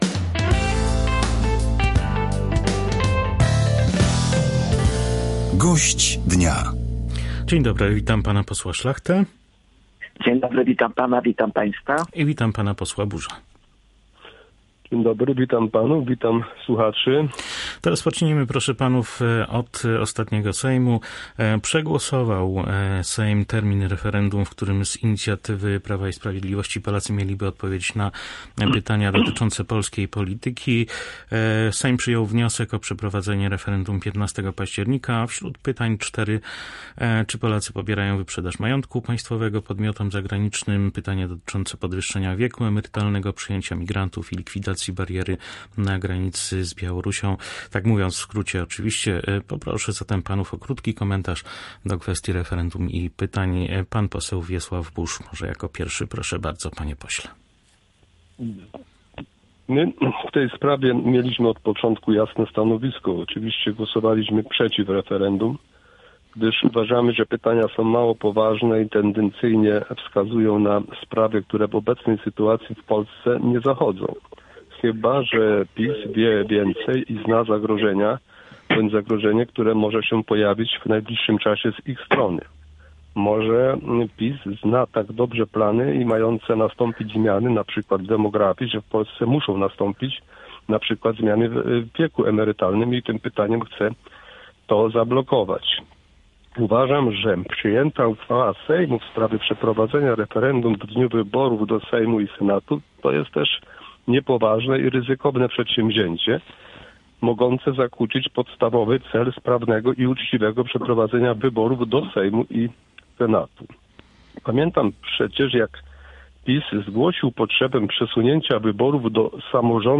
Gość dnia • Podkarpaccy politycy skomentowali na naszej antenie kwestię referendum zaproponowanego przez PiS.
Podkarpaccy politycy skomentowali na naszej antenie kwestię referendum zaproponowanego przez PiS.